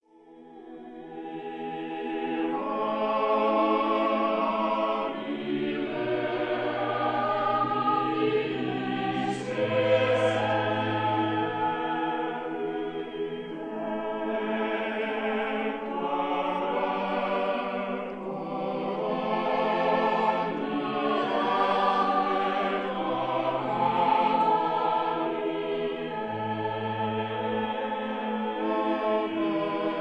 organ
1954 recording made in the Brompton Oratory